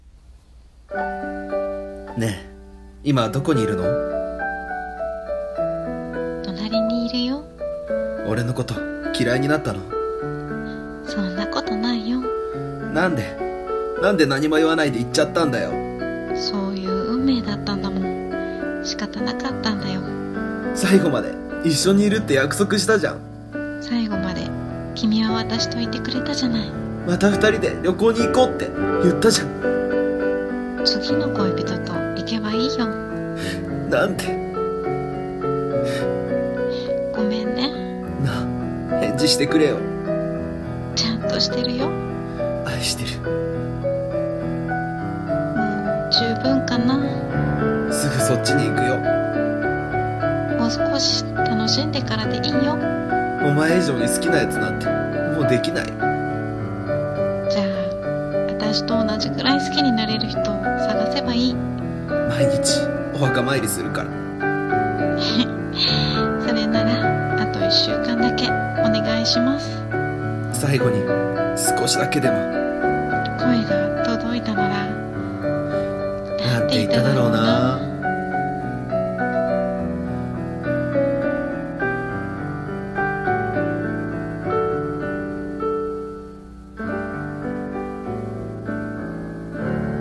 最後の最後に / 声劇